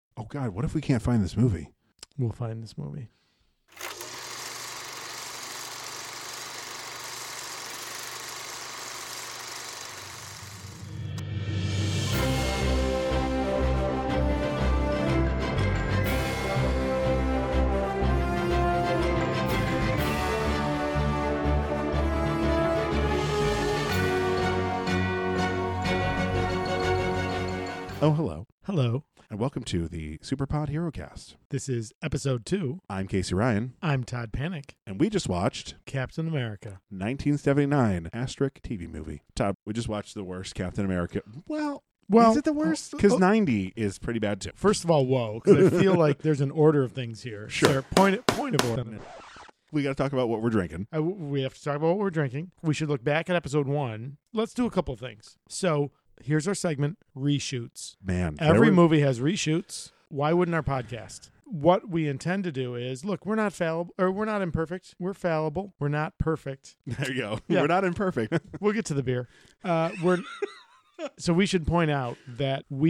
Yup. Talking. Lots and lots of talking.
We are guys with beers talking about movies with capes.